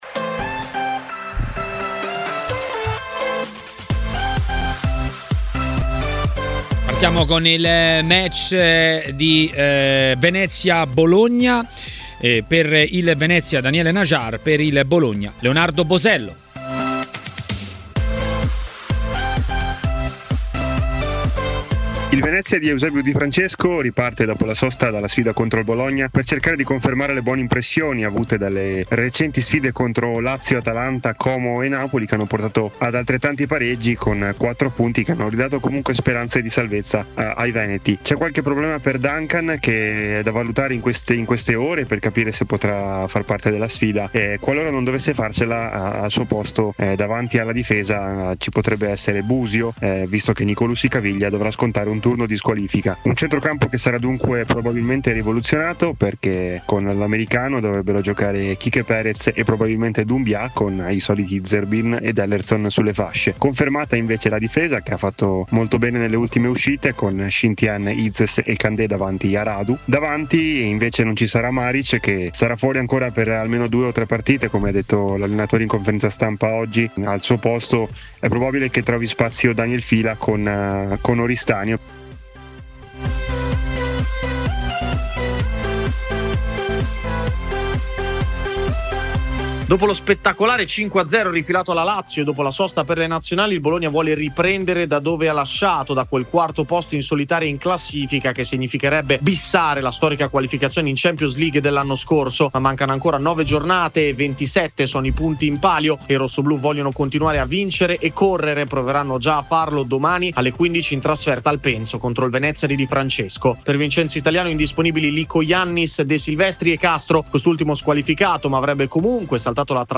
Passa il pomeriggio con Maracanà. Attualità, interviste e tante bufere.